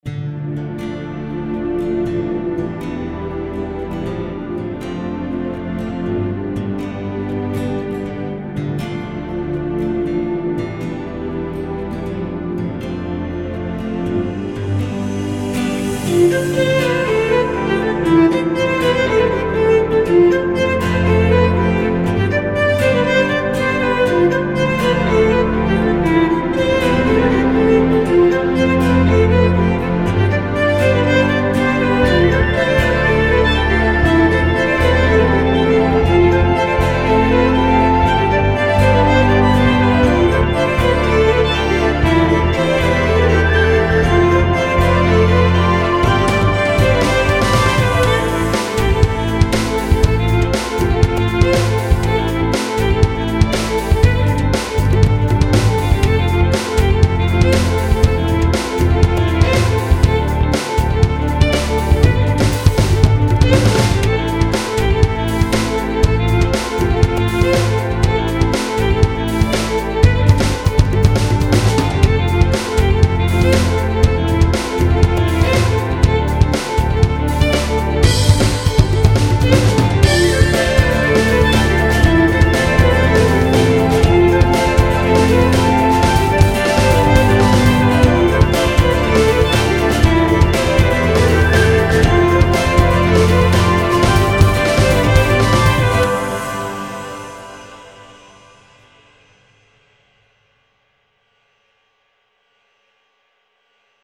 VI Solo Violin for Irish Folk